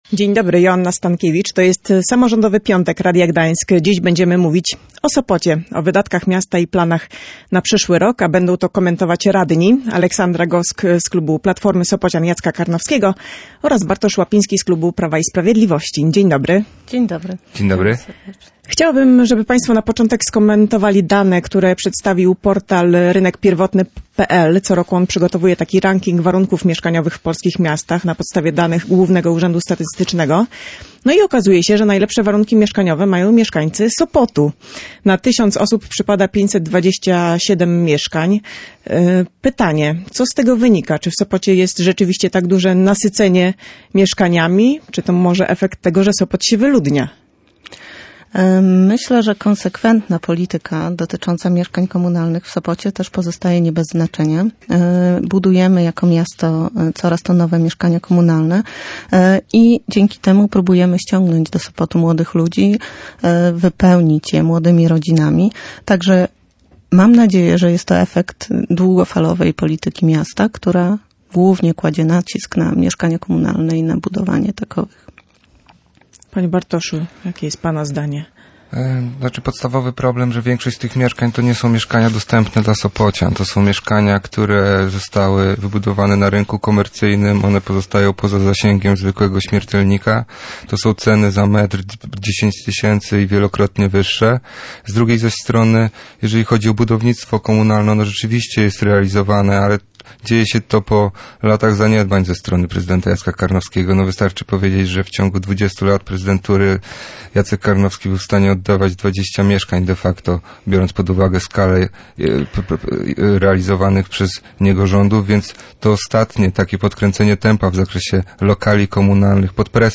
Byli to sopoccy radni Aleksandra Gosk z Platformy Sopocian Jacka Karnowskiego oraz Bartosz Łapiński z Prawa i Sprawiedliwości.